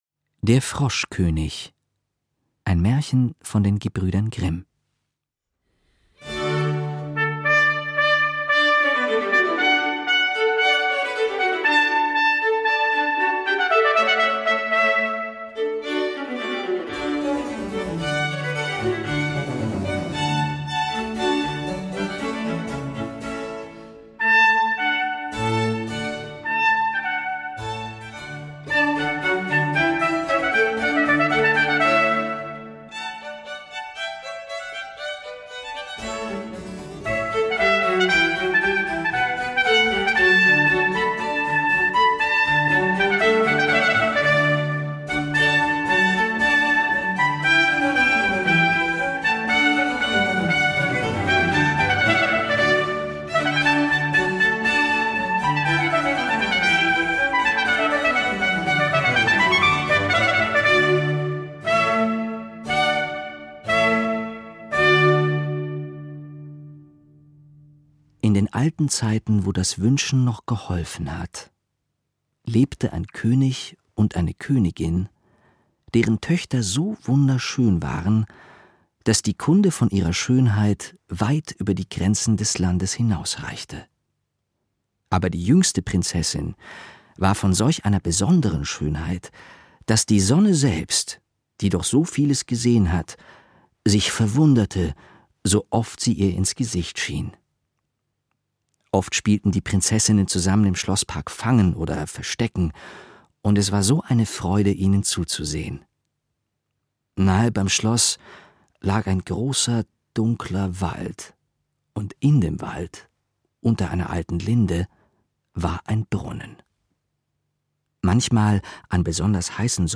Musik von Alessandro Stradella, Georg Friedrich Händel, Heinrich Ignaz, Franz Biber, Pavel Josef Vejvanovsky, Mauricio Kagel, György Ligeti u.a.
Musiker: Wolfgang Bauer Consort